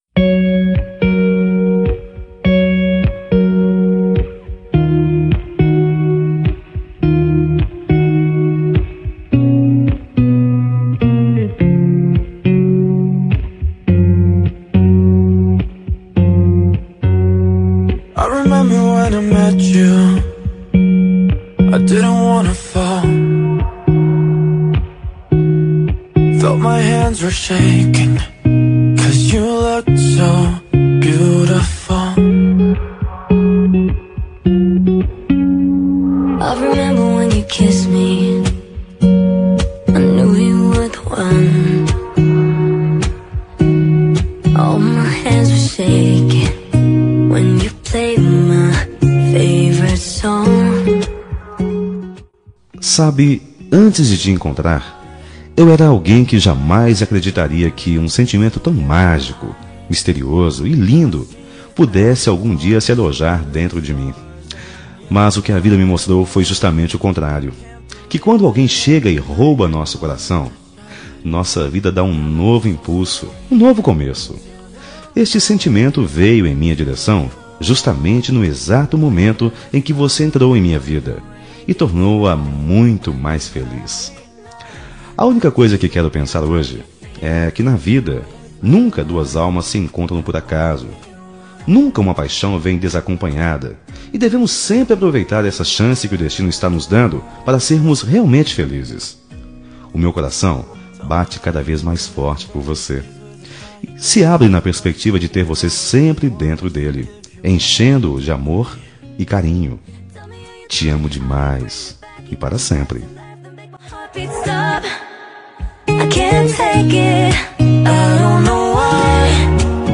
Telemensagem Romântica para Esposa – Voz Masculina – Cód: 9071 – Linda